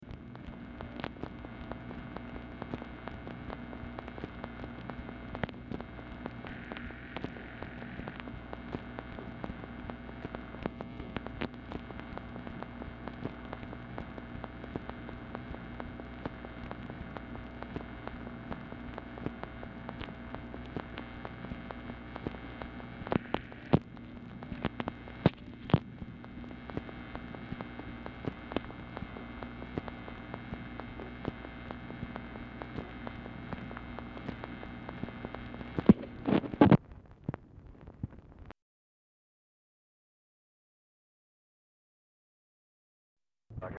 Telephone conversation # 13904, sound recording, MACHINE NOISE, 1/1/1969, time unknown | Discover LBJ
Format Dictation belt
Specific Item Type Telephone conversation